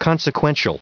165_consequential.ogg